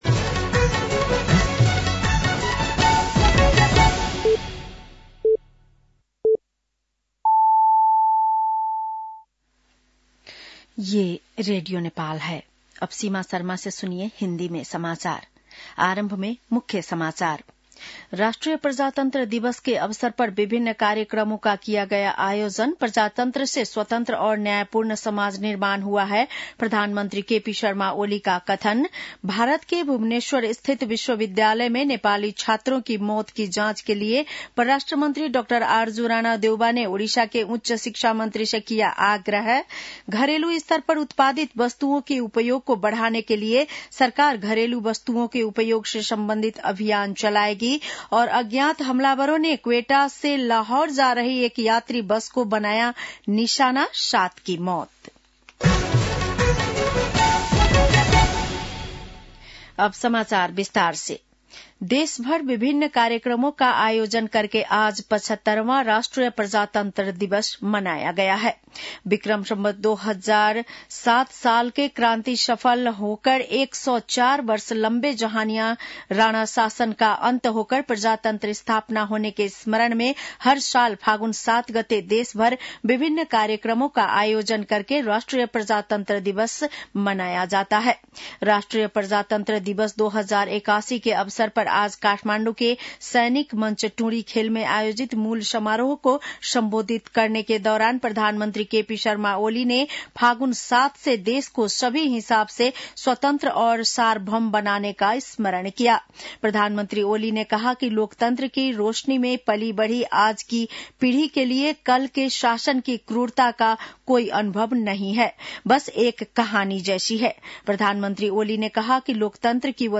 बेलुकी १० बजेको हिन्दी समाचार : ८ फागुन , २०८१